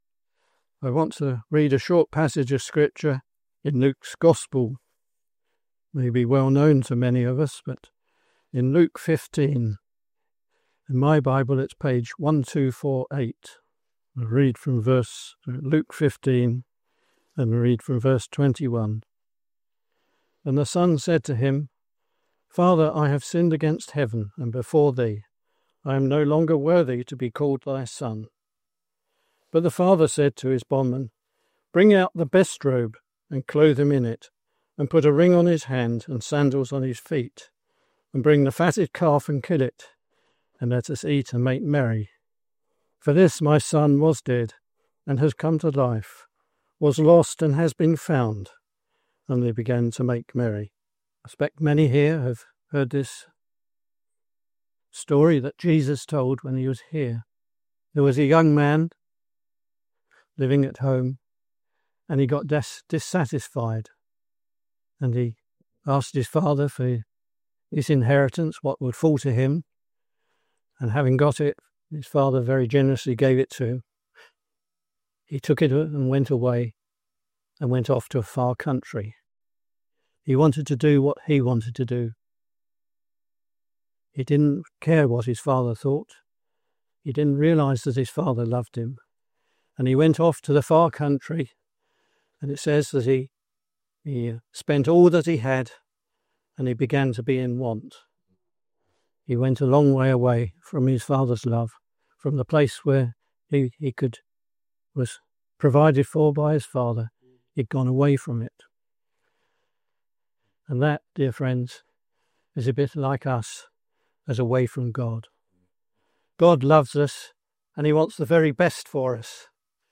In Luke 15, Jesus tells a powerful story of a son who was lost, a father who never stopped loving him, and a homecoming filled with joy. This Gospel preaching explores the parable of the Prodigal Son, revealing the heart of God towards sinners.